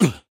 snd_oof2.ogg